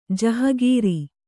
♪ jahagīri